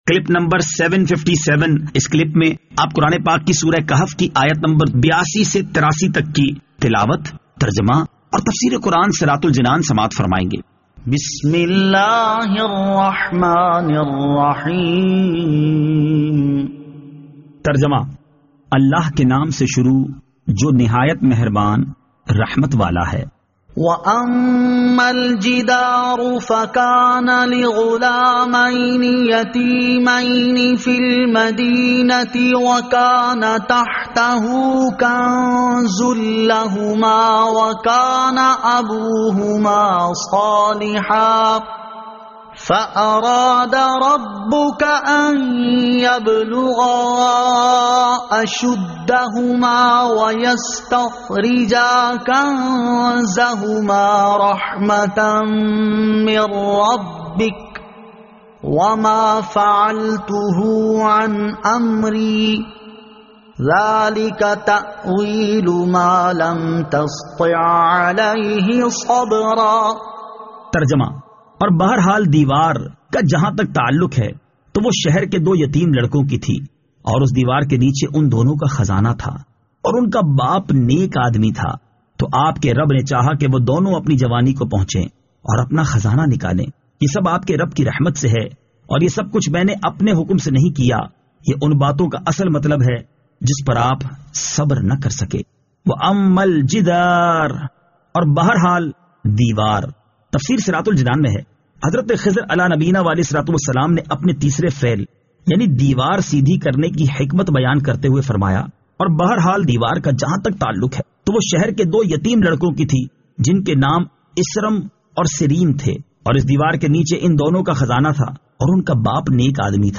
Surah Al-Kahf Ayat 82 To 83 Tilawat , Tarjama , Tafseer